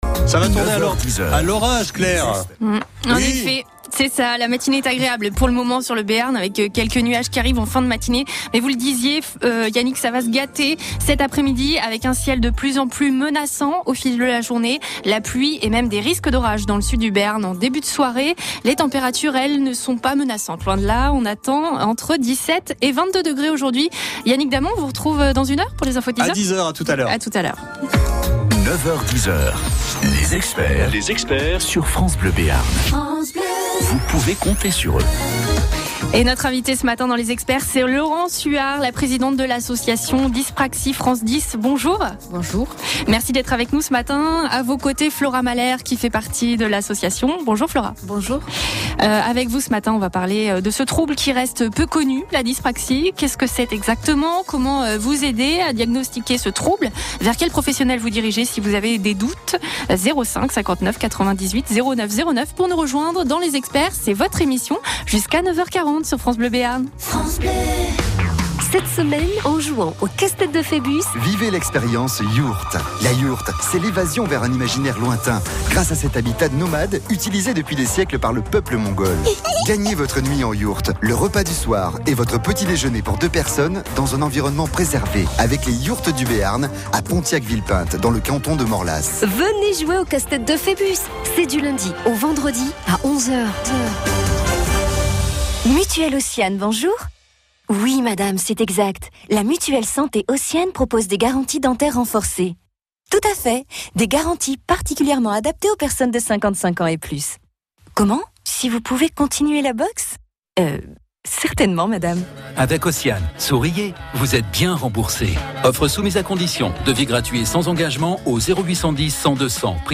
Nous avons eu la chance de participer à l’émission Les Experts sur la radio France Bleue Béarn ce mardi 6 octobre, afin de parler de la dyspraxie et d’annoncer la conférence que nous organisons dans le cadre de la 9ème journée des dys.Une journaliste qui avait préparé l’interview, avait été sur le site de DFD et visualisé le film avec Renan Luce.